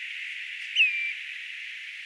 tuollainen nuoren tundrakurmitsan lentoonlähtöääni
Se oli ehkä kyp, tai jotain sellaista.
Ehkä vähän viklomainen hyvin hiljainen ääni.